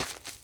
sfx_step_grass_l.wav